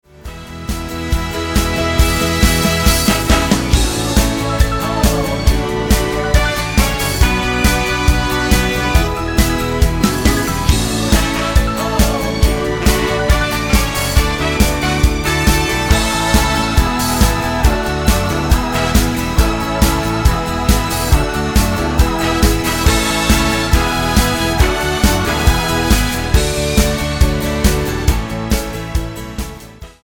Tonart:A mit Chor
Die besten Playbacks Instrumentals und Karaoke Versionen .